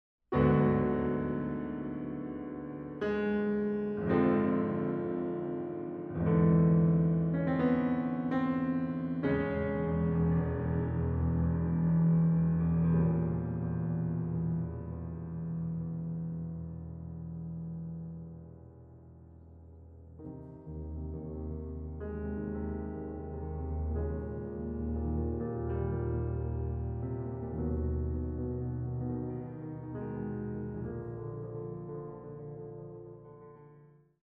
Exquisite sounds well-recorded; excellent booklet.
Piano